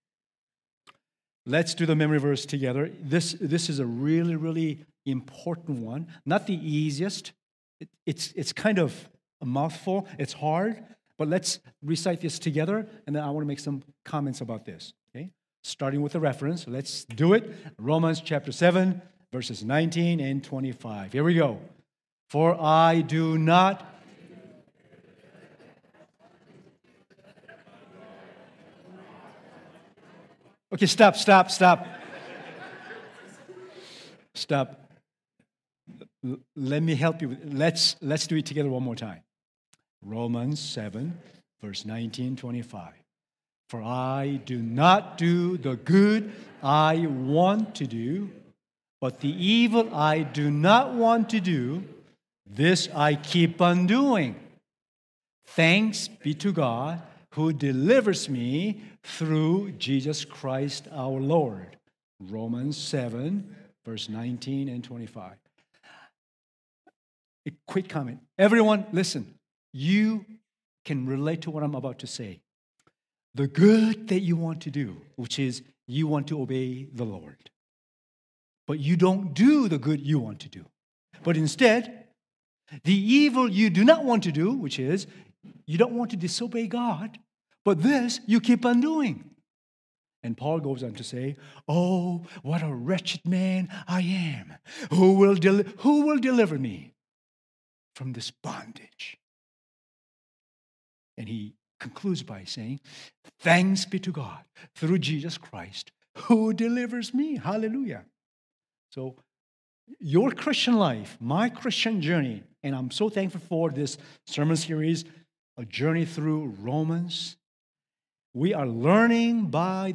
Download Download Reference Romans 8:1-13 Sermon Notes Sermon Notes Feb 9.docx Restored and Renewed Can Anything Separate You from God's Love?